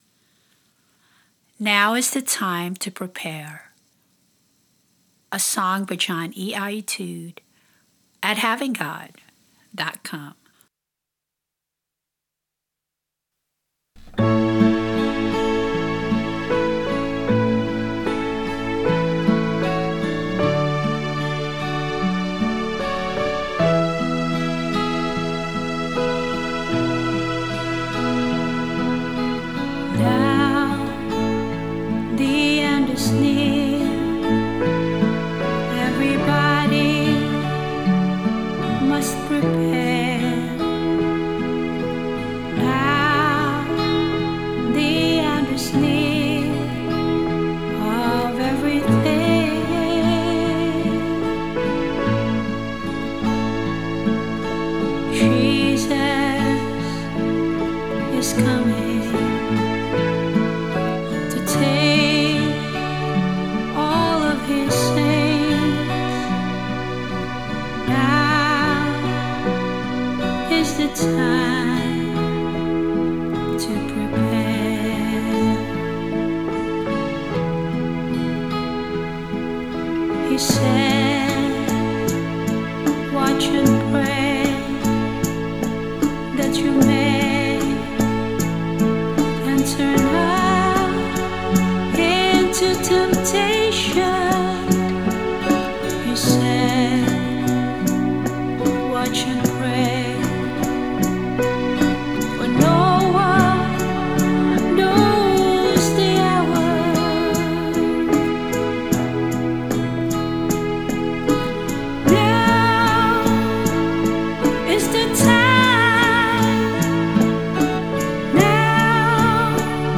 Vocals, guitar, drum machine and bass
Keyboard, strings, and organ